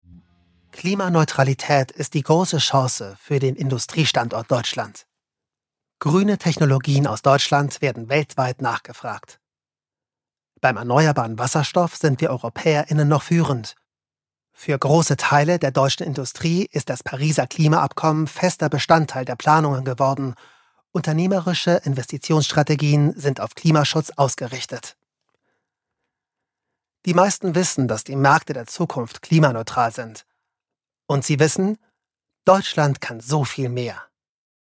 Equipment: Soundblocker Sprecher - Box Mikro Neumann TLM 103 Channelstrip focusrite ISA 220 Wandler Mini - Me von Apogee m-box Muttersprache: deutsch und spanisch
Sprechprobe: Industrie (Muttersprache):